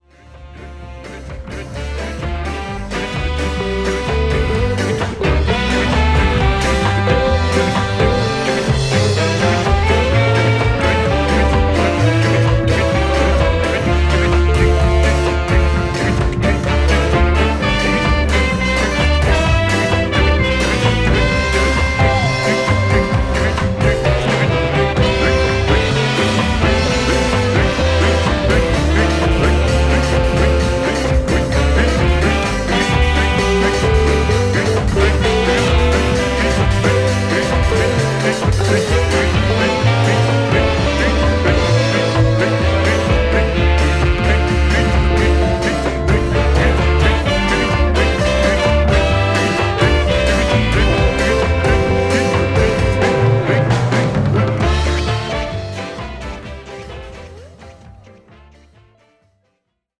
ホーム ｜ JAMAICAN MUSIC > 日本のレゲエ